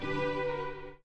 Slide close 4.wav